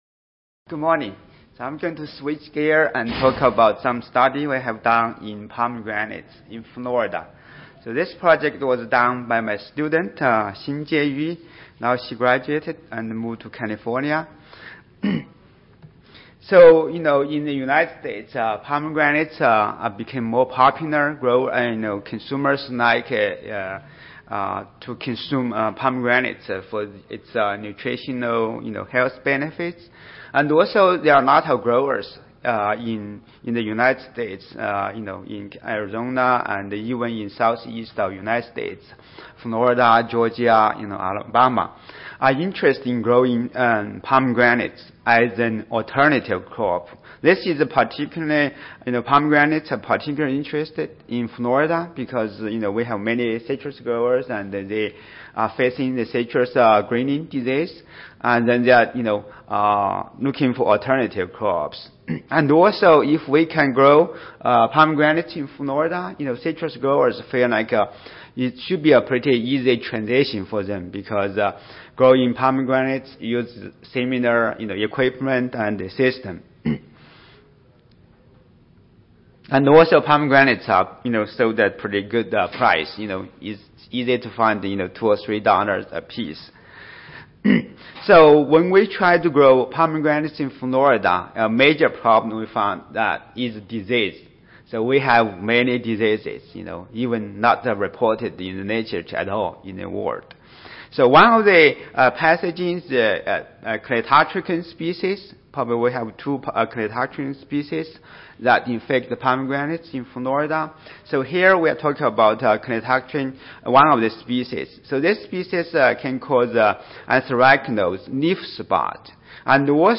University of Florida Audio File Recorded Presentation